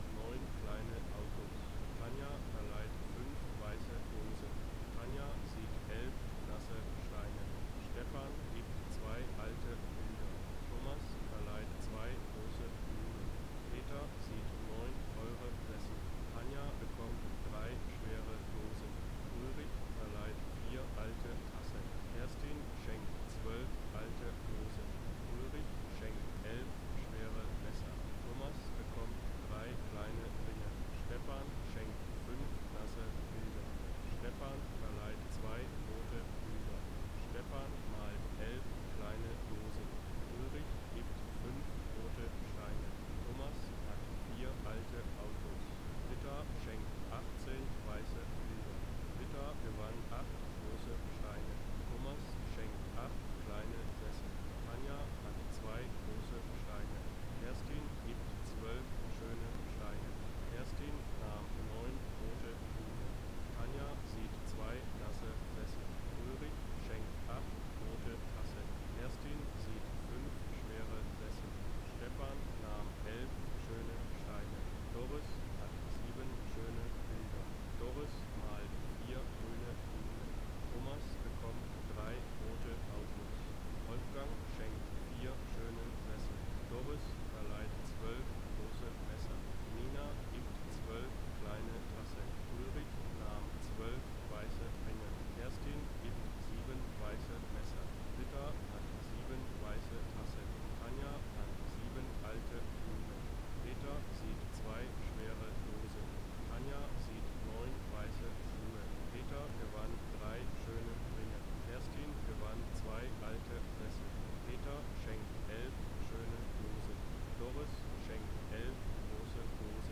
Rauschen mit Sprecher versch_SNR_R45  S42.wav